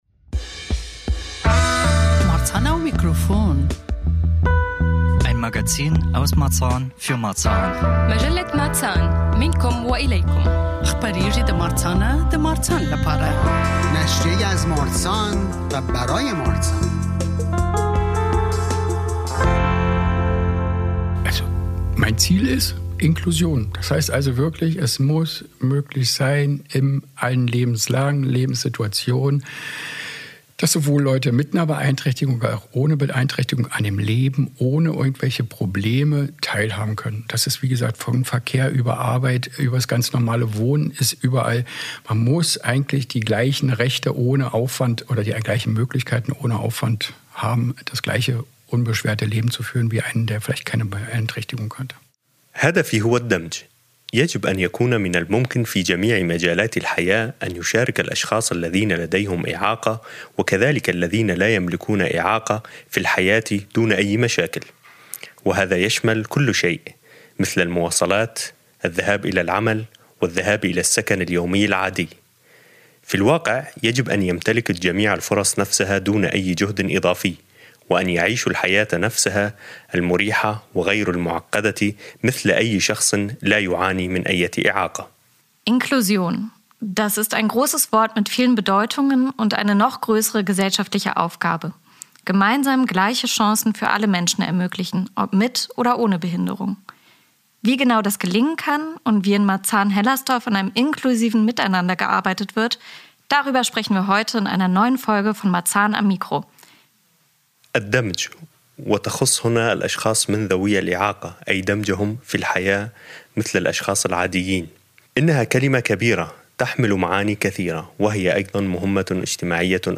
Inklusives Marzahn – Gesprächsrunde